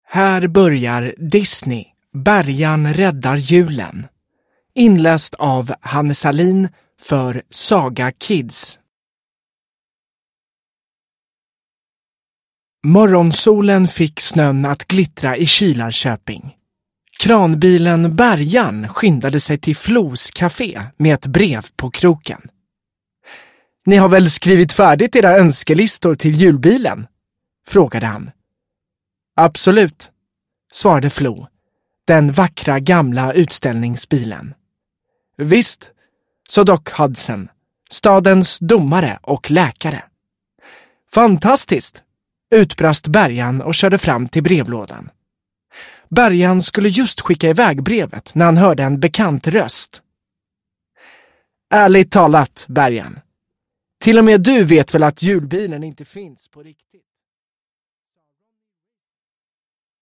Bärgarn räddar julen – Ljudbok